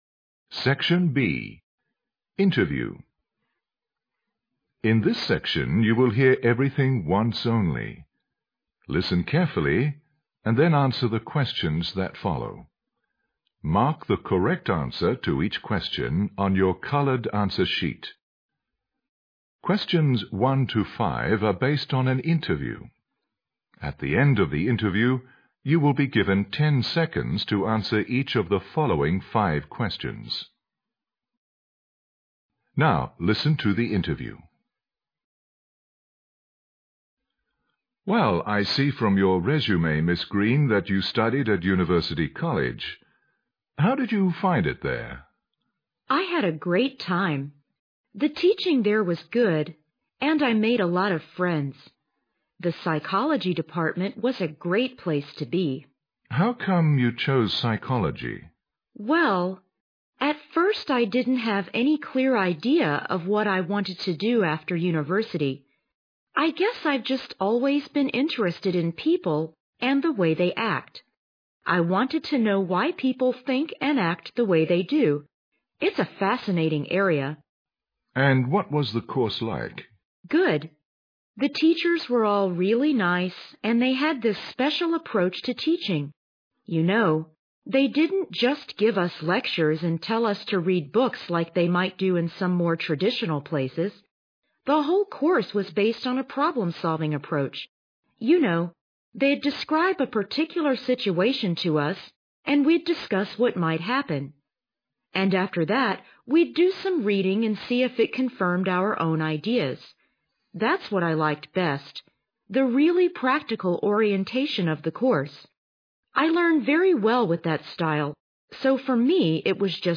SECTION B INTERVIEW